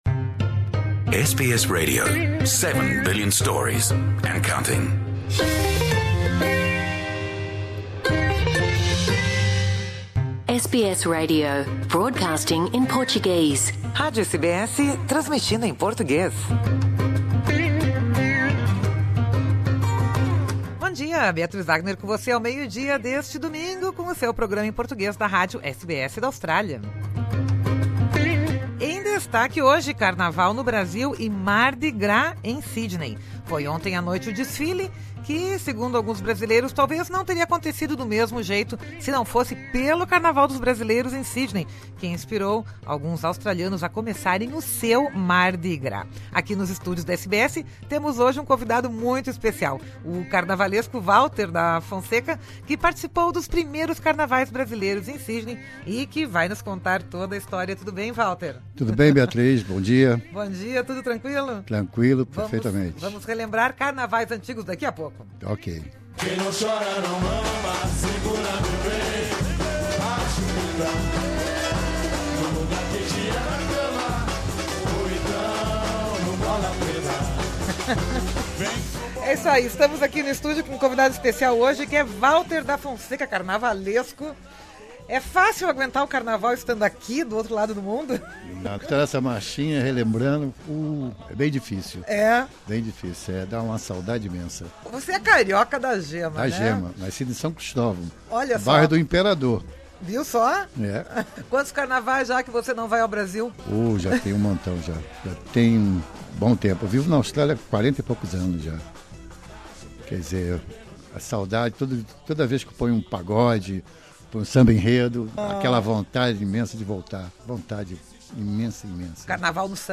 Neste sábado de carnaval aconteceu mais um Mardi Gras de Sydney que, segundo alguns brasileiros, teve a sua origem no carnaval de rua da Brazilian Samba School, em Sydney, que percorreu a George Street até o The Rocks no final dos anos 70. Aqui nos estúdios da SBS, temos um convidado muito especial